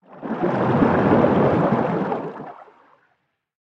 Sfx_creature_arcticray_swim_twist_01.ogg